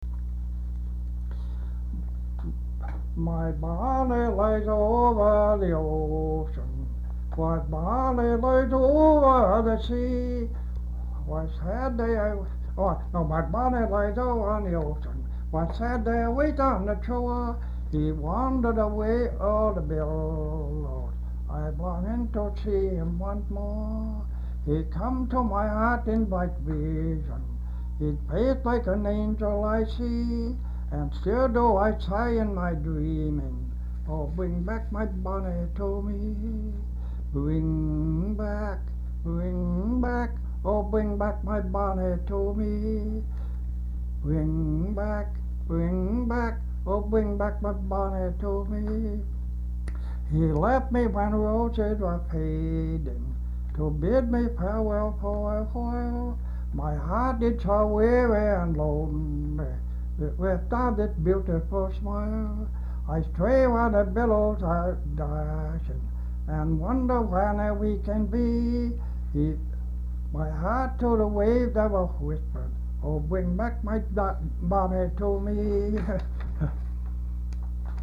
Folk songs, English--Vermont
sound tape reel (analog)
Location Marlboro, Vermont